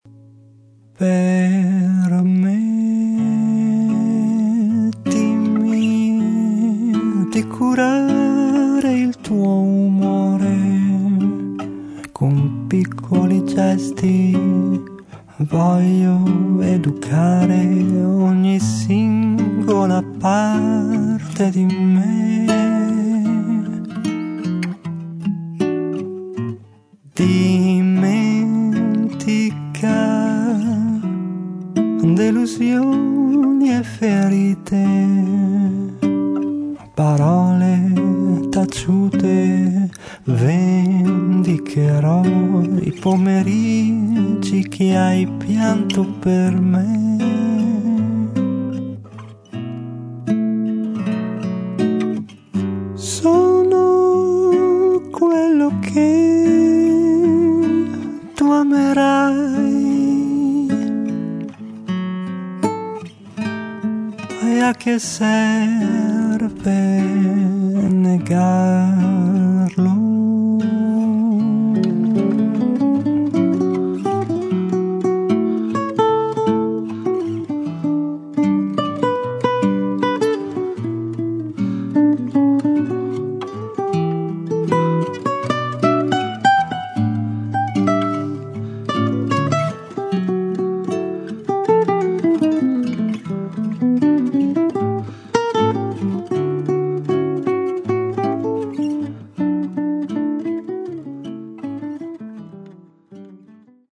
registrato e missato alla
chitarre acustiche e classiche